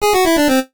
Music Jingles / Audio (Retro)
jingles-retro_06.ogg